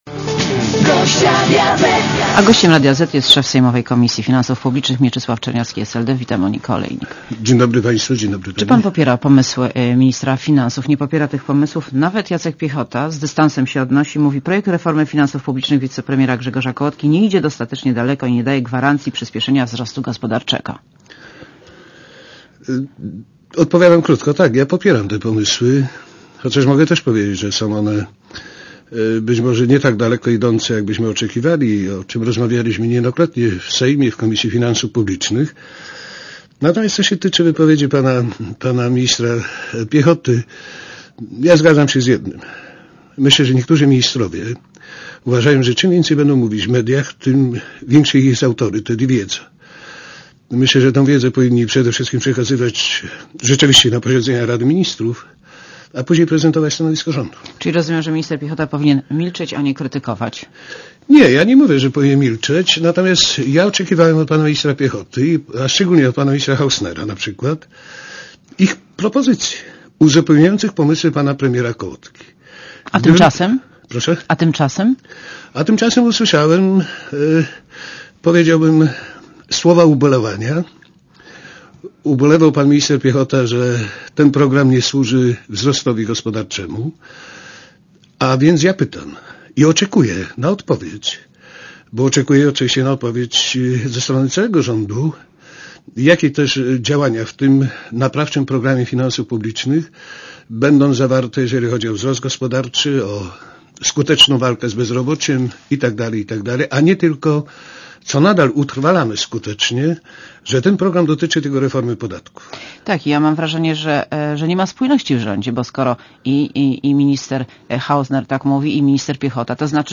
Monika Olejnik rozmawia z Mieczysławem Czerniawskim z SLD, szefem sejmowej Komisji Finansów Publicznych